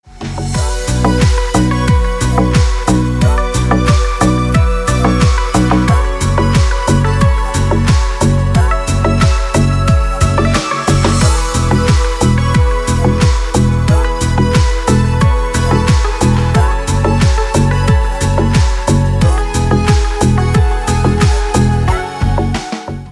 • Категория: Красивые мелодии и рингтоны